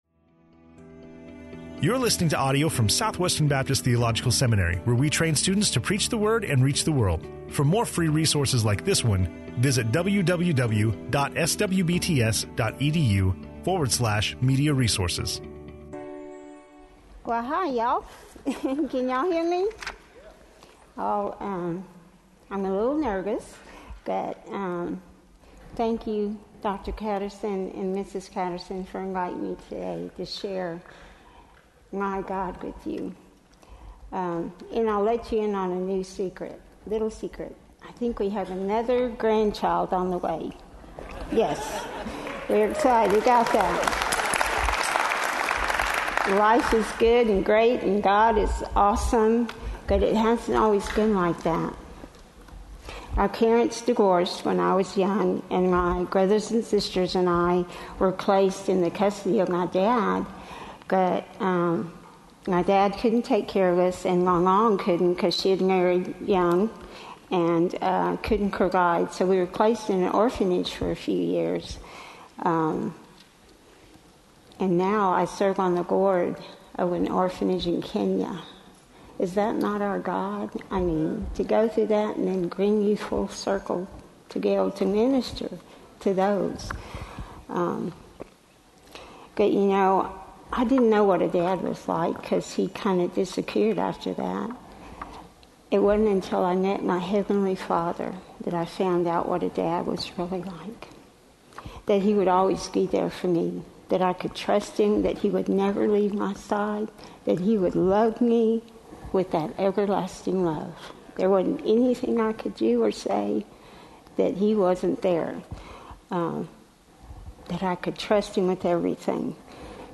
Testimony
SWBTS Chapel